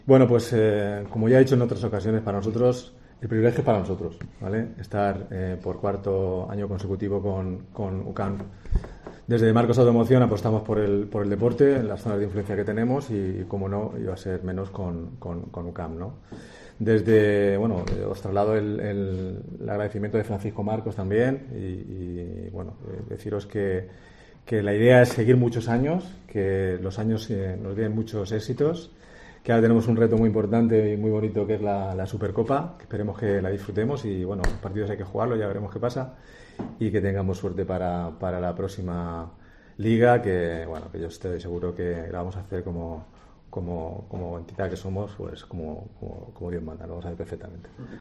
En sus primeras palabras ante los medios reconoció que su fichaje por el club murciano es "un paso adelante" en su carrera y se mostró "muy contento de poder estar aquí".